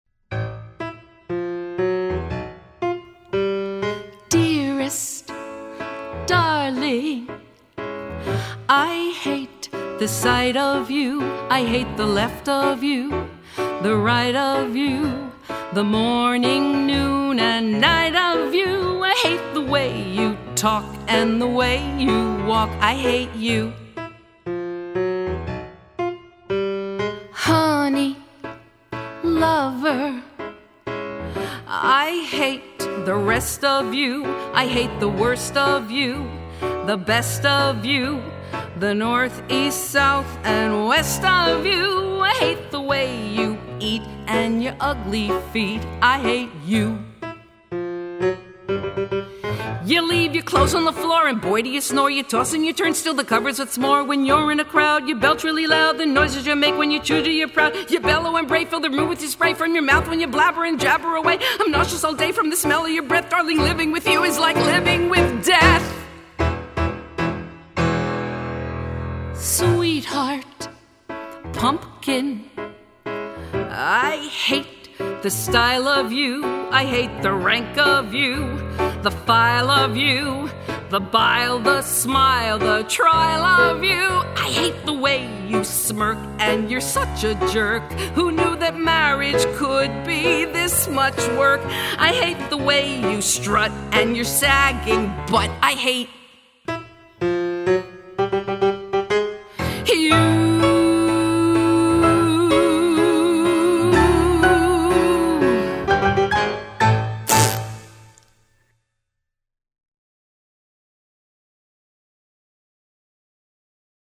Here is another short ditty that I wrote for the musical in Writer’s Block.